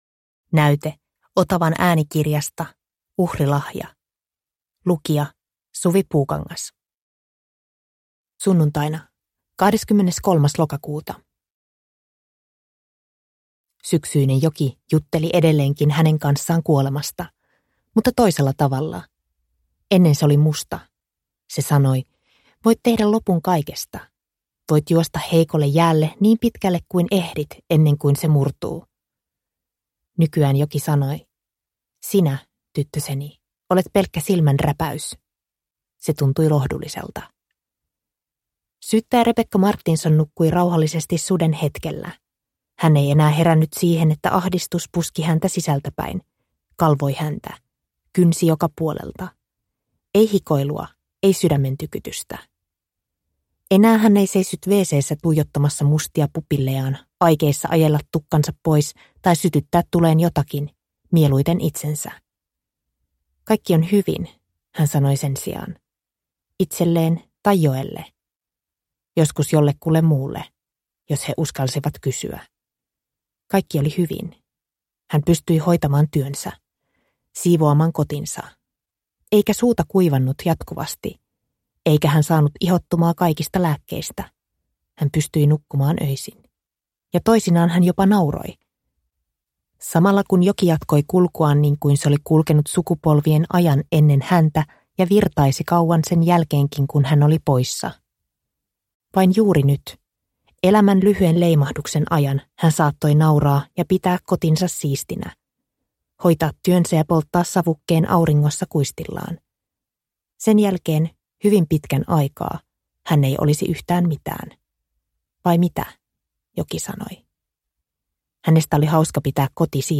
Uhrilahja – Ljudbok – Laddas ner